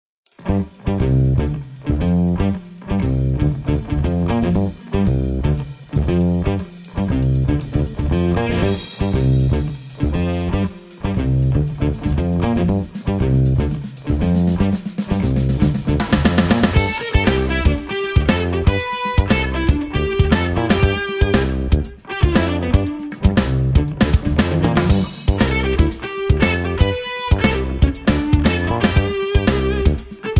basso elettrico
chitarra